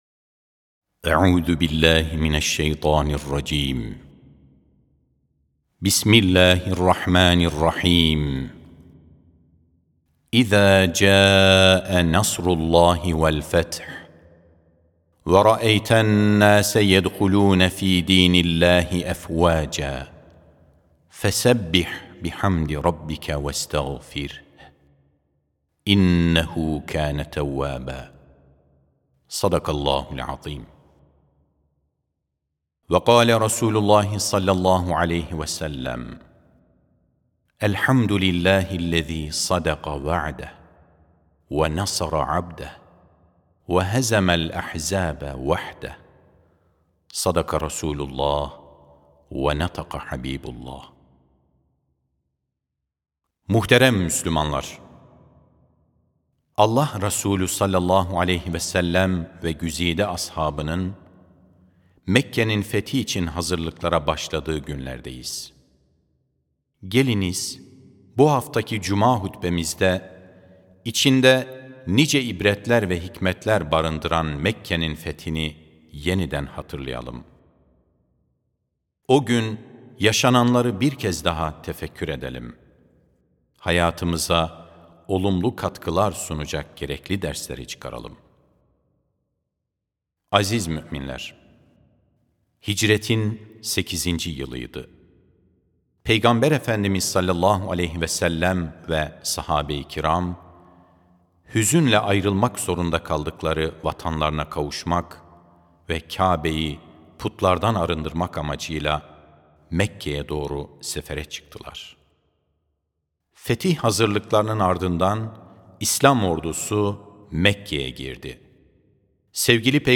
Sesli Hutbe (Her Zorluktan Sonra Kolaylık Vardır).mp3